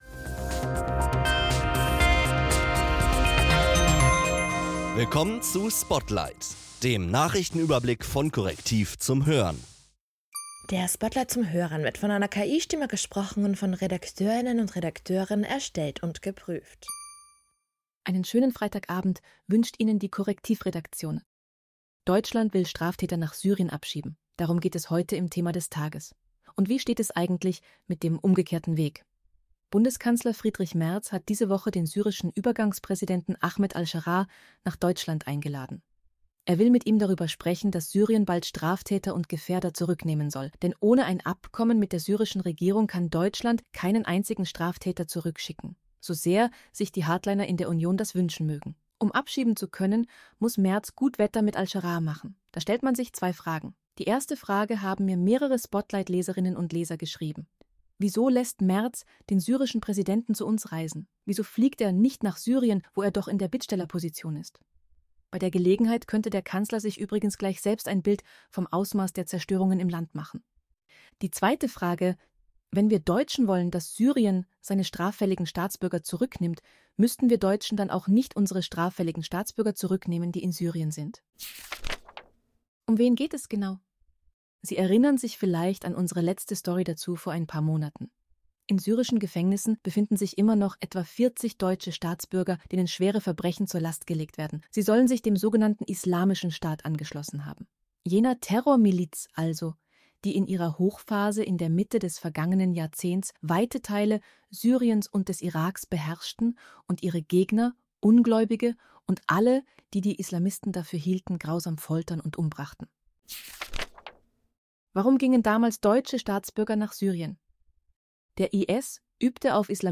Diese für Audio optimierte Kompaktfassung des täglichen Spotlight-Newsletters ist von einer KI-Stimme eingelesen und von Redakteuren erstellt und geprüft.